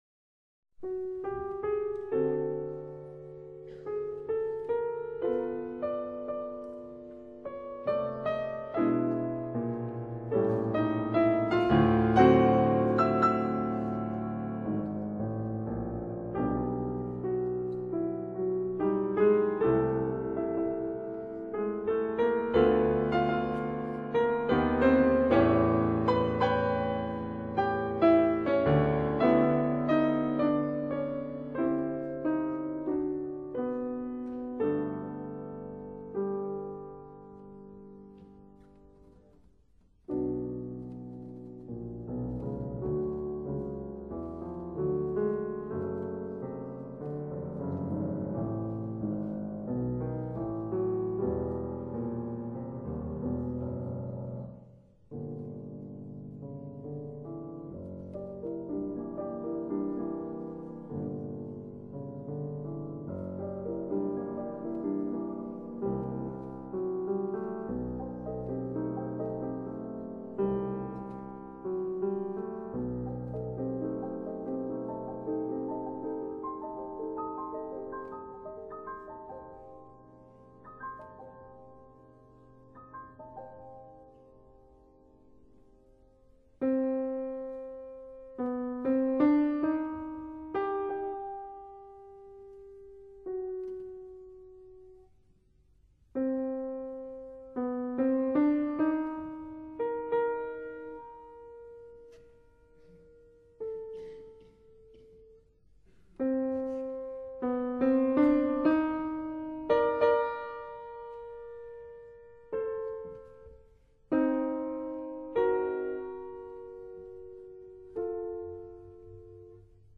중간부에는 그와 같은 주장조로 템포가 빠르고 경쾌한 악구가 연주된다.
Sviatoslav Richter, Piano